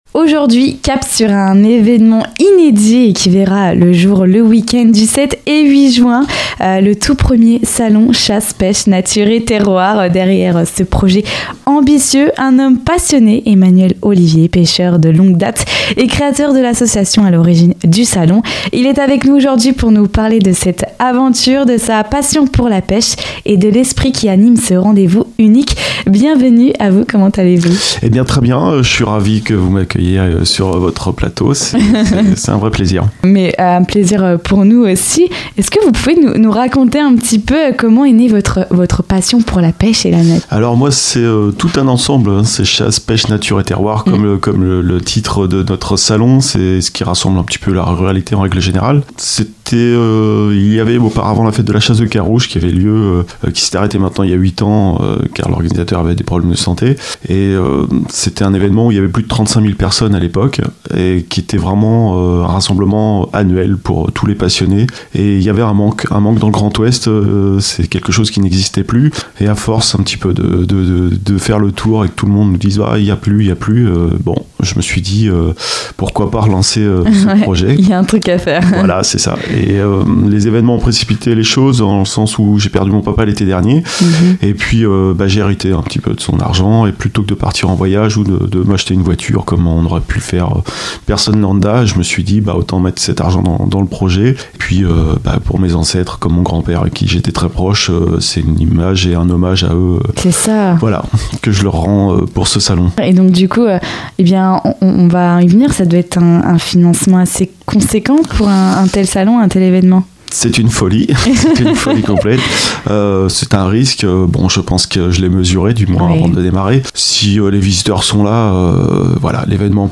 Il est venu dans les locaux de Radio Pulse pour nous emmener dans les coulisses de l’organisation de ce salon. Un salon qui a lieu le week-end du 7 et 8 juin à l'Espace Anova. Pour en savoir plus sur cet rencontre n'hésitez pas à écouter jusqu'au bout l'interview.